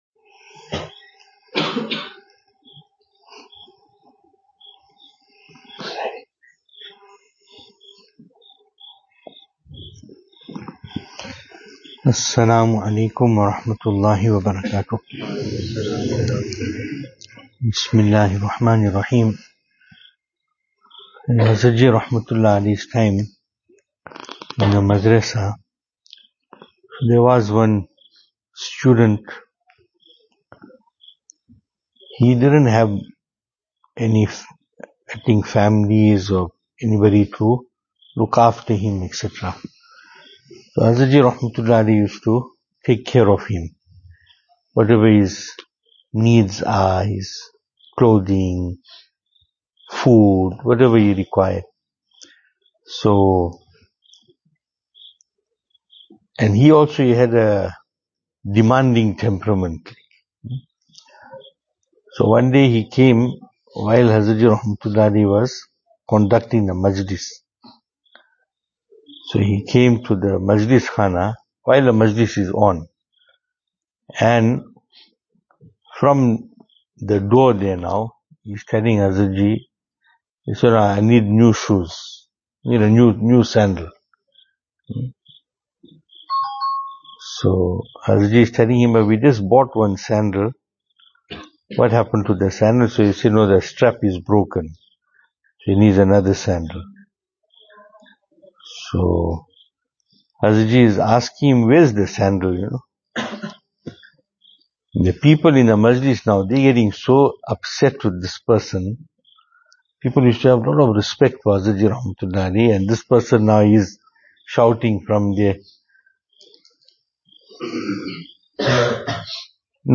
Venue: Albert Falls , Madressa Isha'atul Haq
Service Type: Islahi Majlis